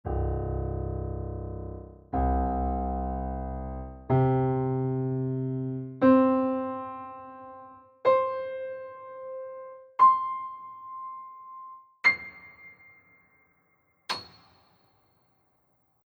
Here are all eight C’s on the piano. They are different in pitch, but all have the same character.